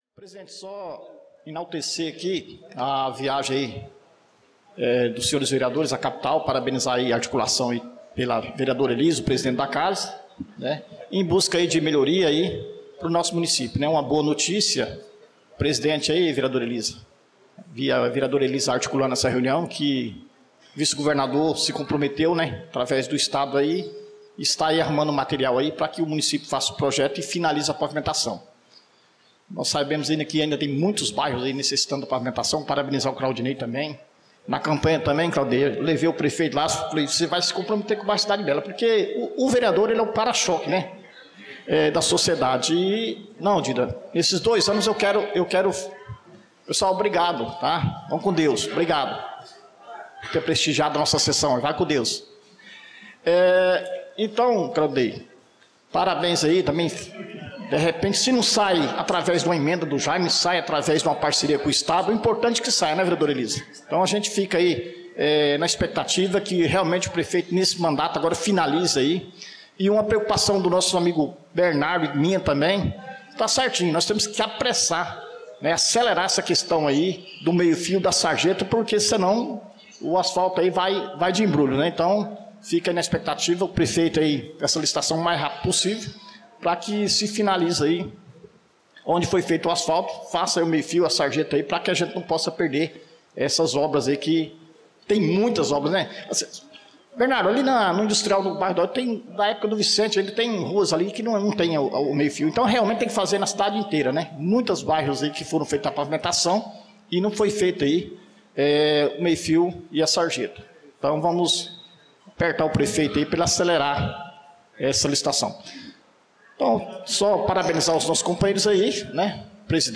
Pronunciamento do vereador Dida Pires na Sessão Ordinária do dia 18/02/2025